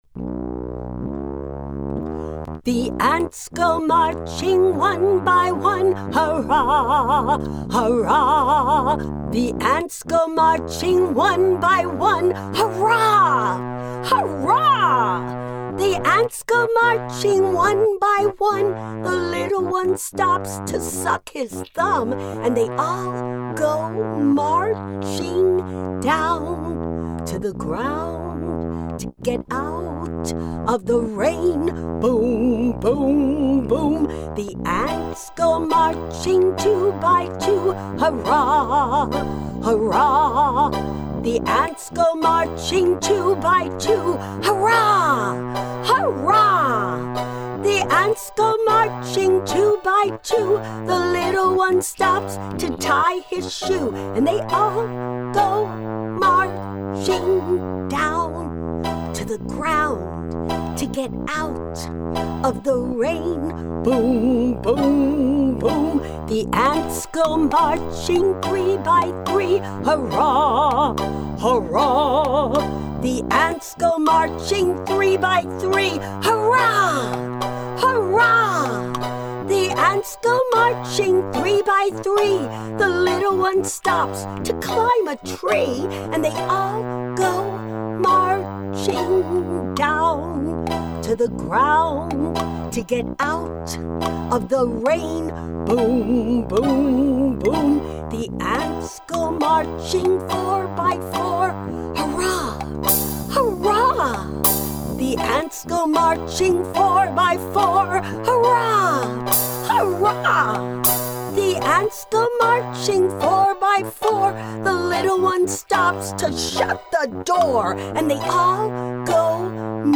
Genre: Childrens.